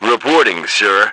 1 channel
H_soldier2_13.wav